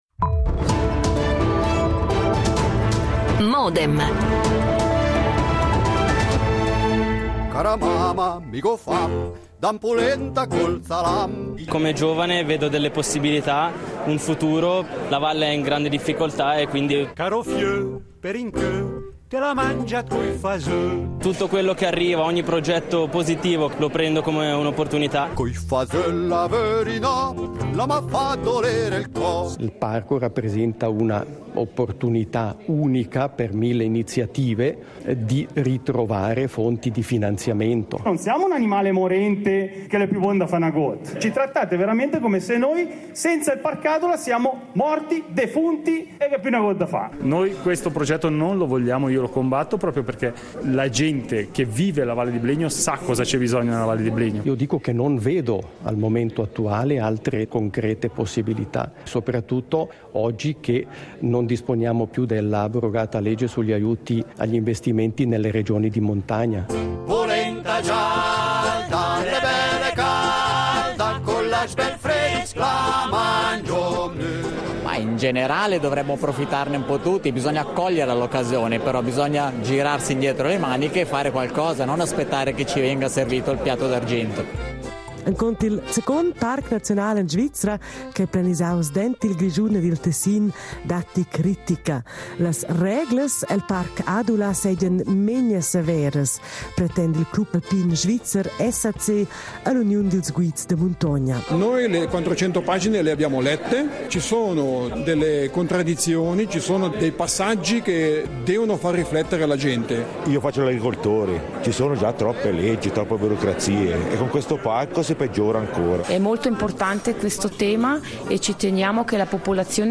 Un grande dibattito pubblico tra pro e contro il nuovo parco nazionale